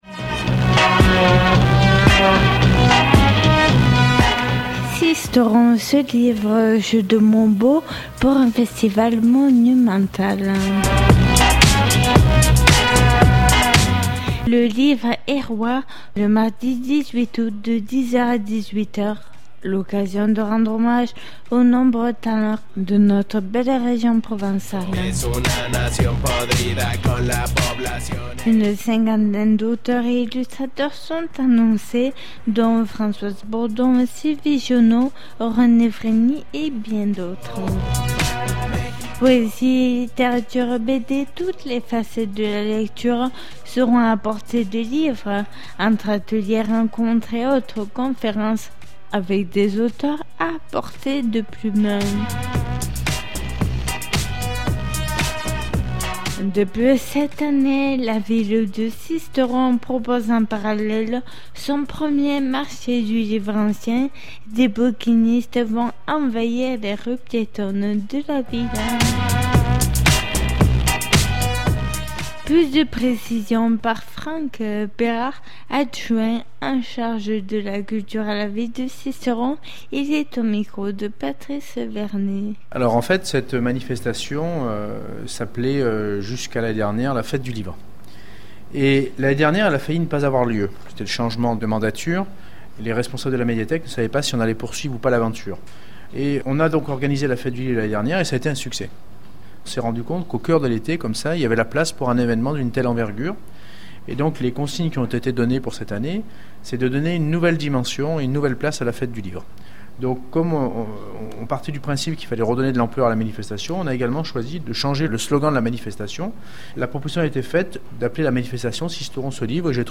Plus de précisions par Franck Pérard, Adjoint en charge de la culture à la ville de Sisteron.